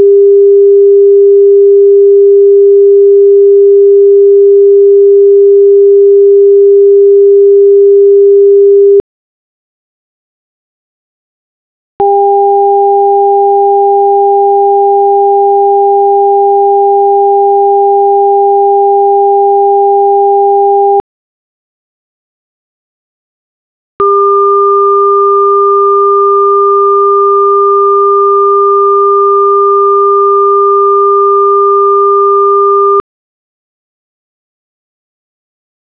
400 Hz
400 Hz joined by 802 Hz
400 Hz joined by 1202 Hz
You will hear high metallic overtones at loud volume.